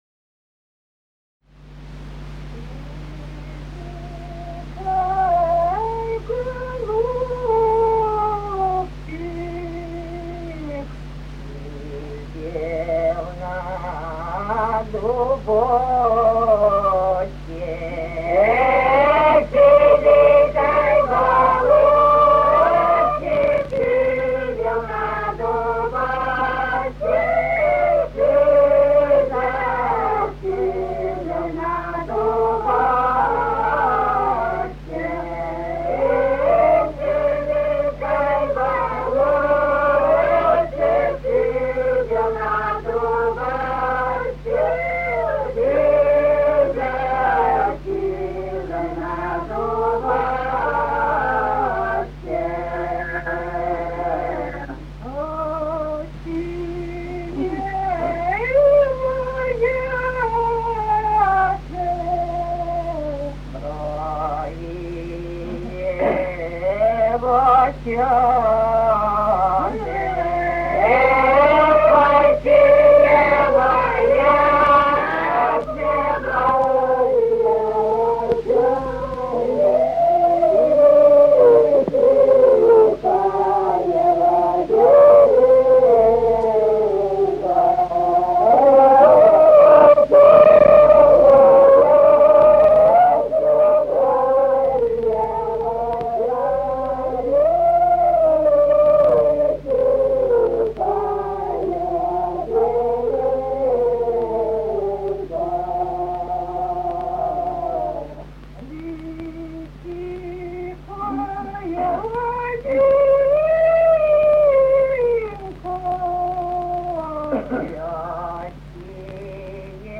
Русские народные песни Владимирской области 33. Сизенький голубчик (лирическая) с. Михали Суздальского района Владимирской области.
исполняет ансамбль.